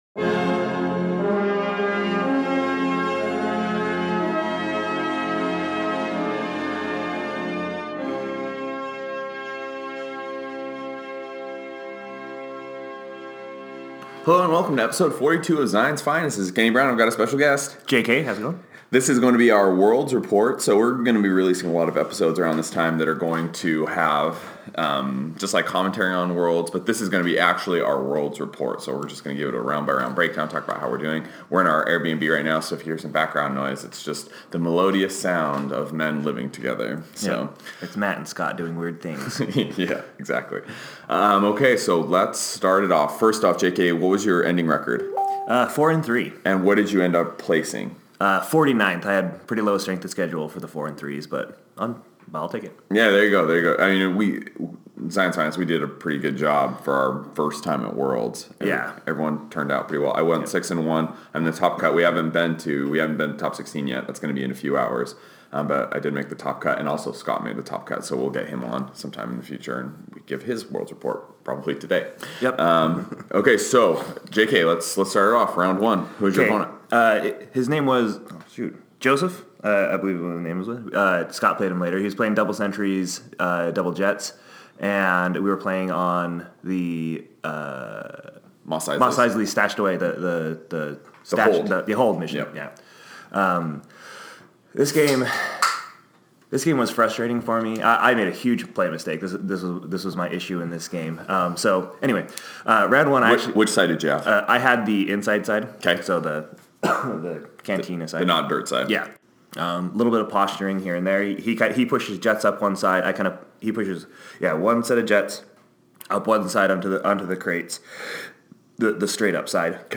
The episode was recorded on Thursday morning, so we start off a bit groggy but pull it out in the end.